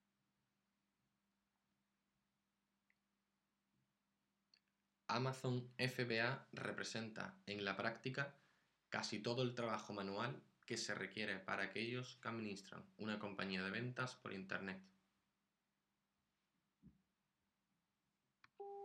I think is a good quality but RMS is out of standards
The track has very low volume.
I’m trying with an external slightly professional microphone and audacity